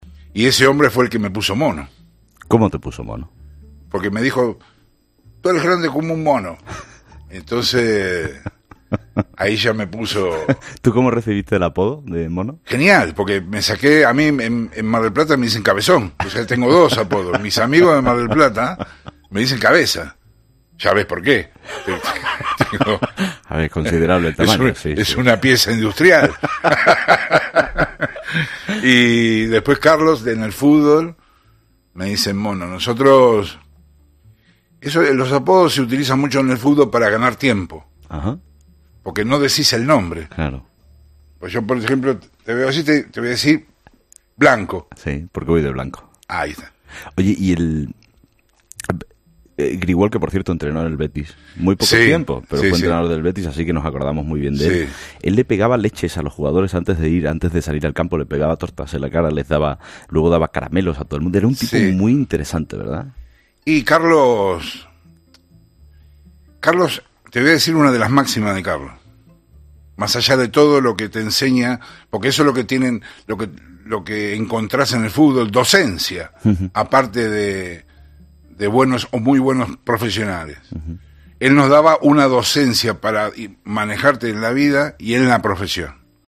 "Me dijo: Tu eres grande como un mono", comentó entre risas.
Germán 'Mono' Burgos en Herrera en COPE.